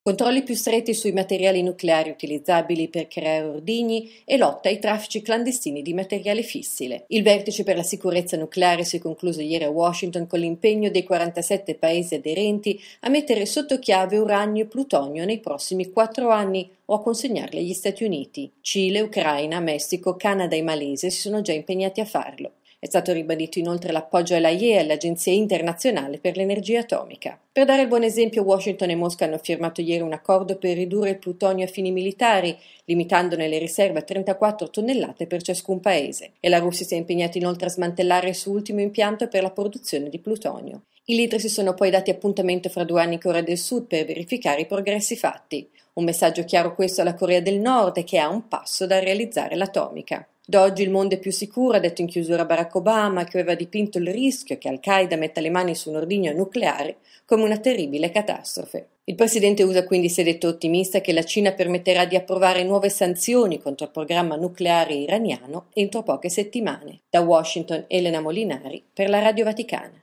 Dagli Stati Uniti, il servizio